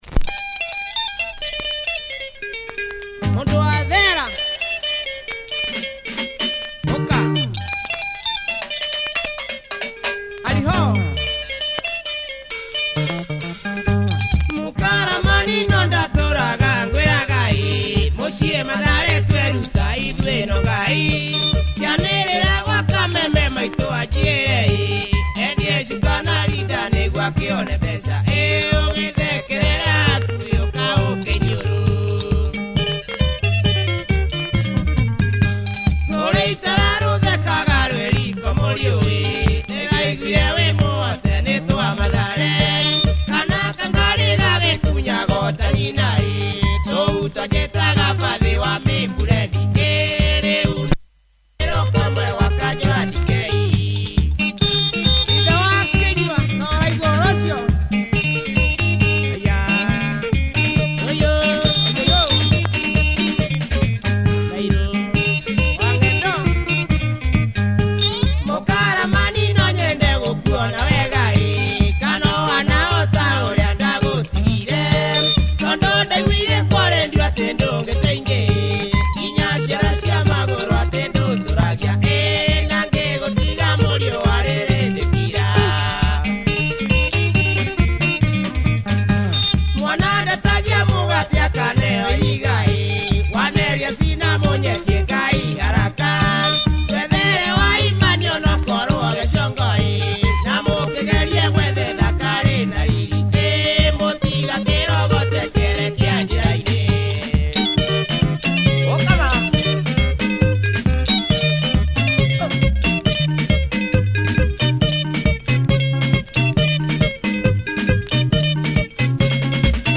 Superb punch and tempo
good dancefloor track if you're a DJ!